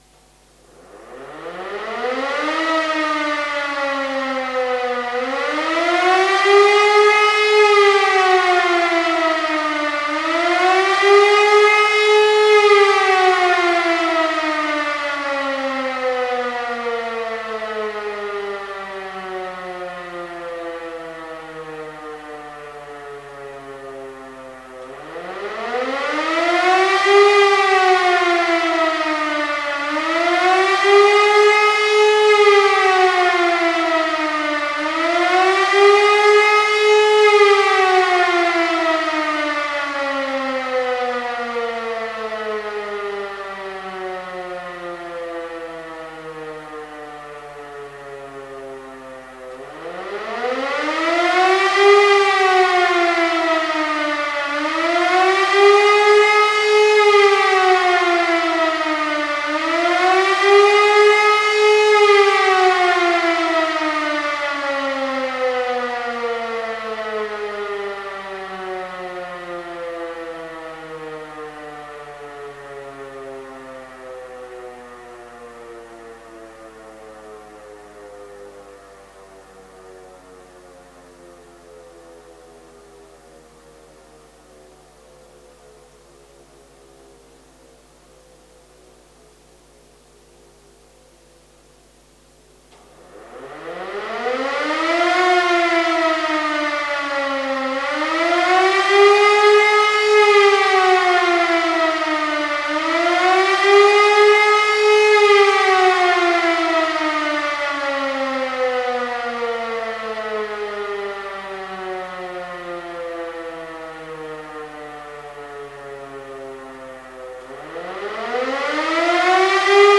ABC-Alarm
Das Sirenensignal eines ABC-Alarms ist eine einminütige Sequenz, in welcher ein permanent auf- und abschwellender Heulton zweimal für je fünfzehn Sekunden unterbrochen wird. Nach einer Pause von 30 Sekunden erfolgt eine Wiederholung dieser Sequenz.
sirenensiegnal-abc-alarm.mp3